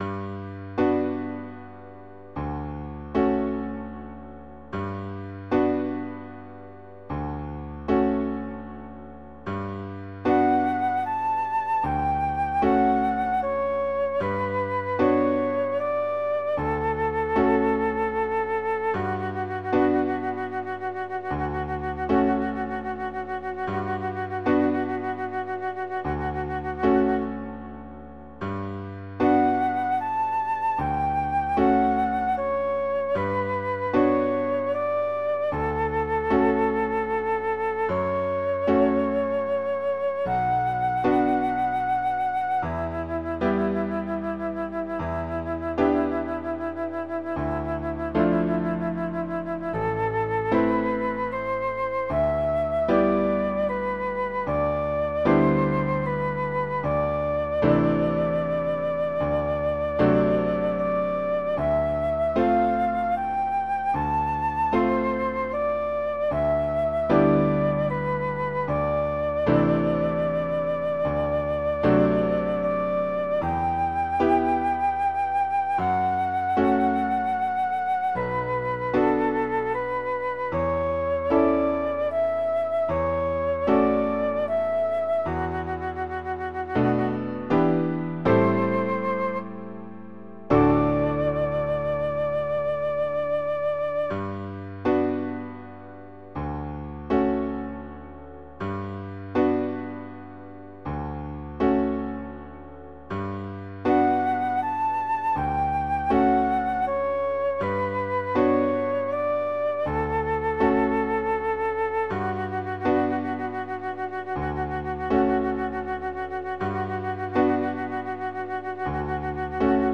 フルート・アンサンブルのオススメ：
・ジムノペディ：森の中に彷徨い込んだような大人サウンド！ピアノの絶妙な音の変化もしっかり聴かせる優れモノ
Free-scores.com_satie-erik-trois-gymnopedies-pour-flute-et-piano-42932.mp3